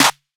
SOUTHSIDE_snare_lo_clap.wav